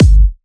VFH2 128BPM Tron Quarter Kick.wav